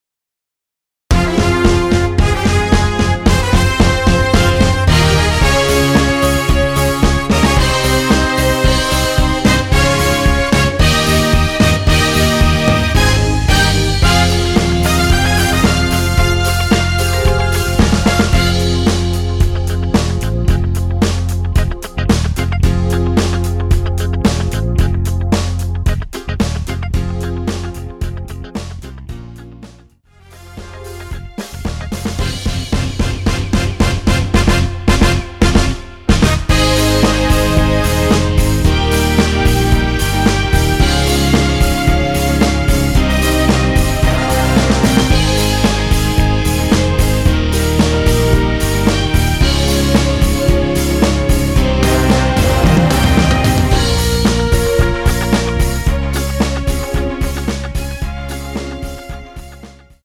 원키에서(-2)내린 MR입니다.
Fm
앞부분30초, 뒷부분30초씩 편집해서 올려 드리고 있습니다.
중간에 음이 끈어지고 다시 나오는 이유는